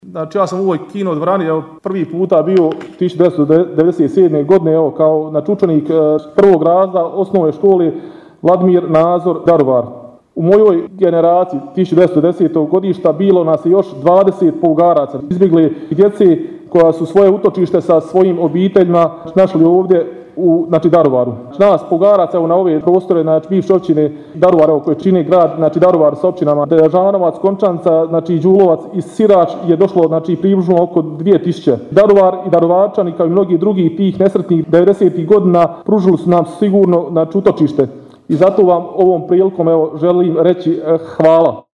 Svečana sjednica u povodu Dana Grada Daruvara održana je u dvorani Gradskog kina Pučkog otvorenog učilišta.